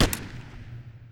SMG1_Shoot 02.wav